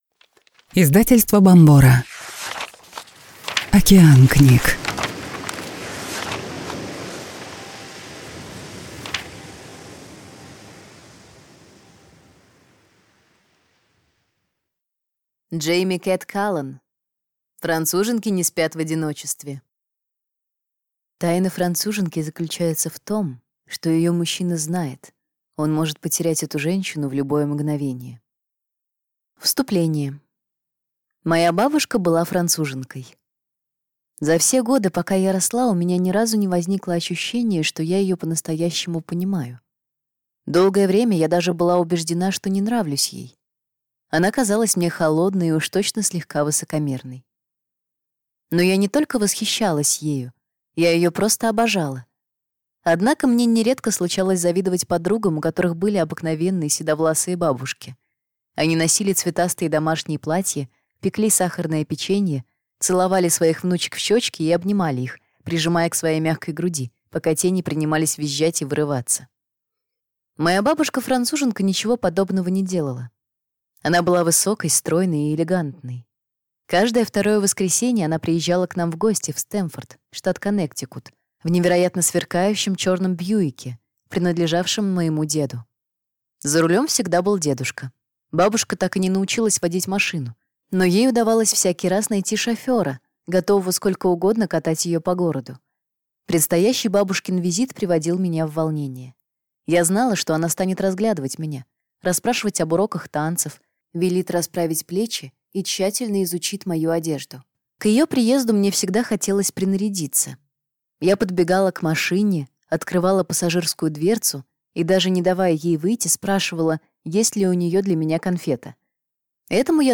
Аудиокнига Француженки не спят в одиночестве | Библиотека аудиокниг